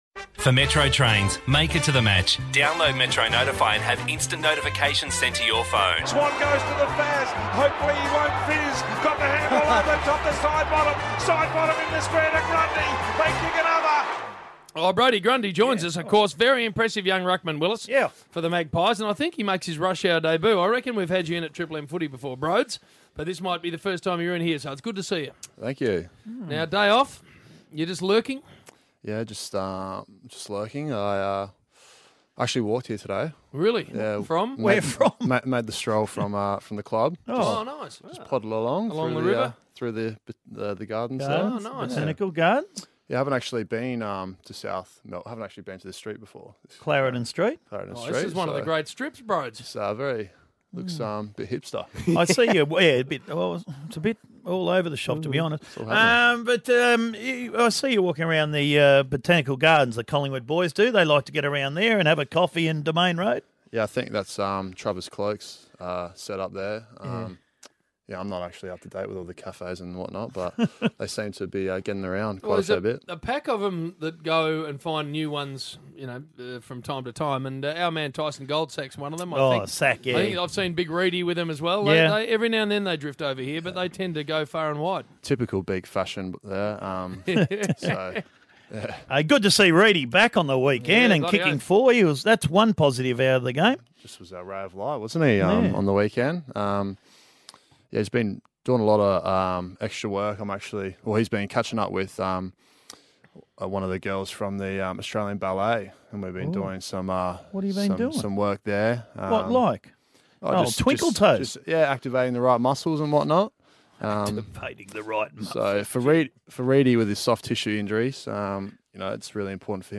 Radio: Brodie Grundy on Triple M
Listen to Brodie Grundy join Triple M's Rush Hour team on Tuesday 11 August 2015.